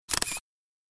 screen-capture.mp3